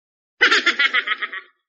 eatbulagalaugh1.mp3